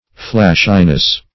Flashiness \Flash"i*ness\, n.